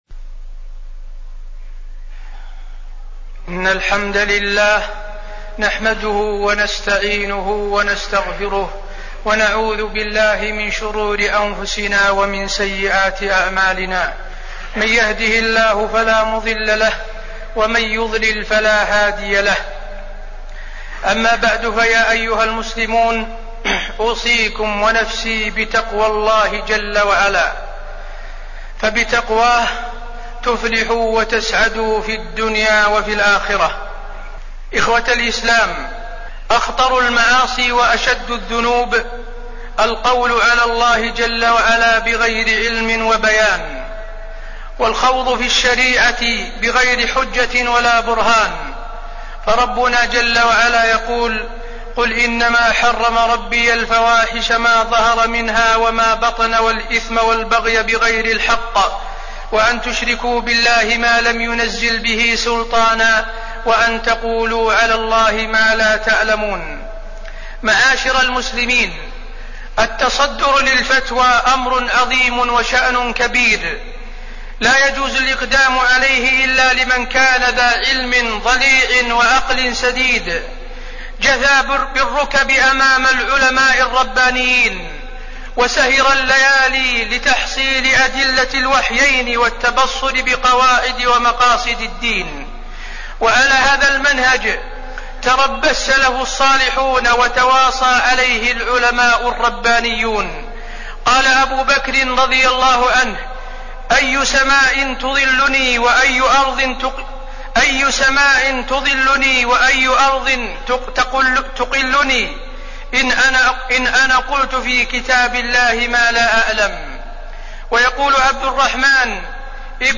تاريخ النشر ١٦ ربيع الأول ١٤٣٠ هـ المكان: المسجد النبوي الشيخ: فضيلة الشيخ د. حسين بن عبدالعزيز آل الشيخ فضيلة الشيخ د. حسين بن عبدالعزيز آل الشيخ الفتوى وضوابطها